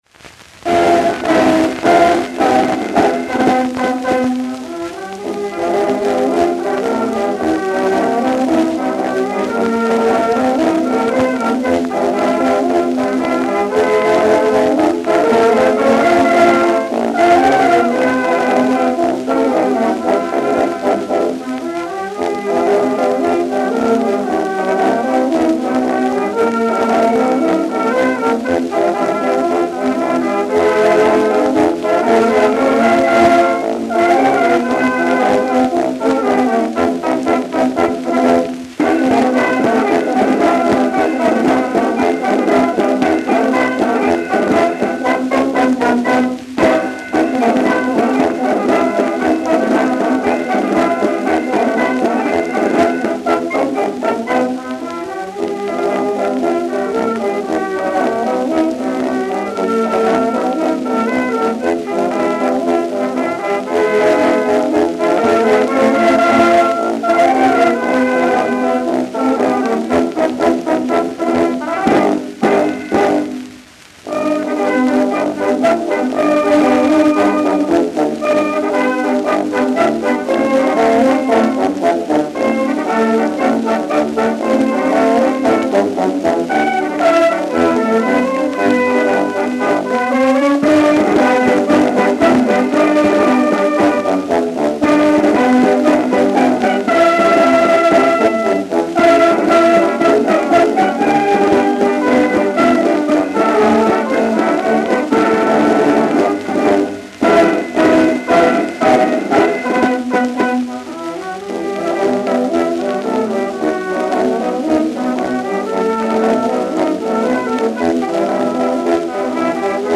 Старинный русский марш.
1910 год, исполняется полковым оркестром одного из знаменитых гусарских полков России.
Оркестръ I Сумскаго Гусарск. полка